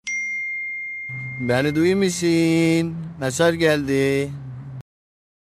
Zaza Dayı Mesaj Bildirim Sesi
Kategori: Zil Sesleri
Hızlıca indirip kolayca kurabileceğiniz bu özel bildirim sesi, her mesajda Zaza Dayı'nın unutulmaz repliğiyle sizi karşılayacak.
zaza-dayi-mesaj-bildirim-sesi-tr-www_tiengdong_com.mp3